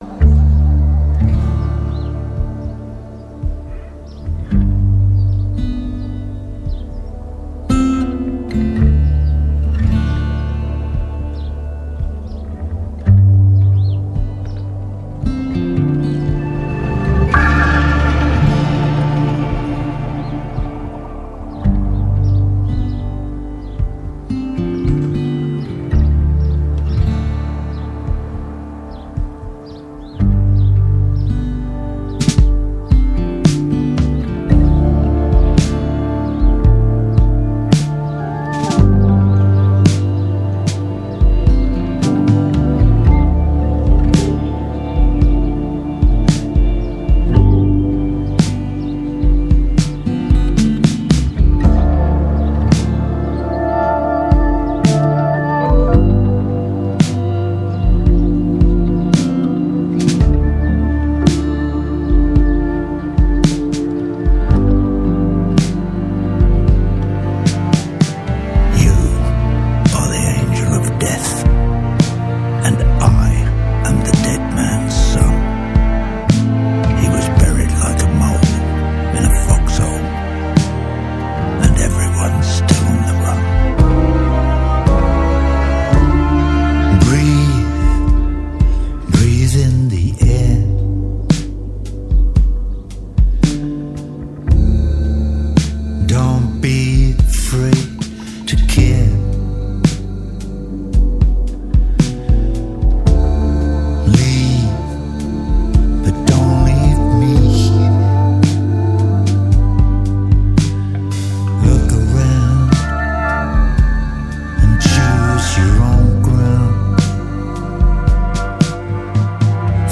آلبوم زنده راک
Rock, Progressive Rock